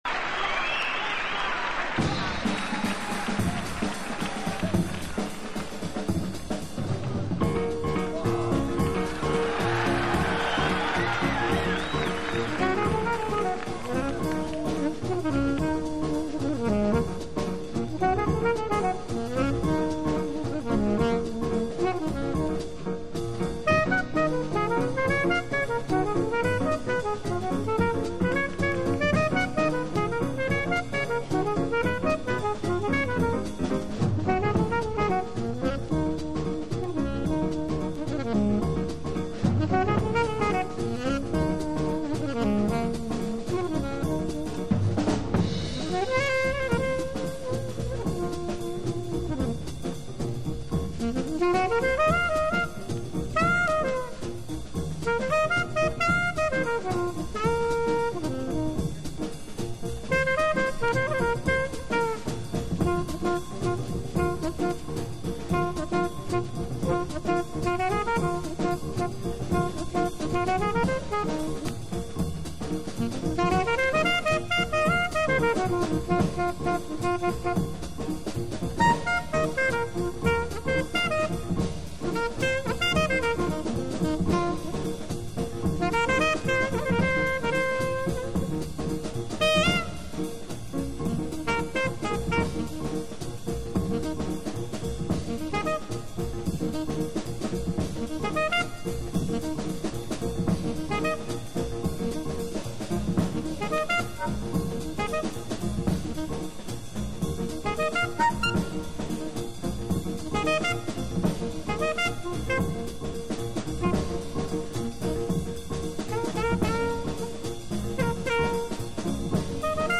Live名盤。
（プレスによりチリ、プチ音ある曲あり）※曲名を…